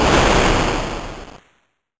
explosion_tanque.wav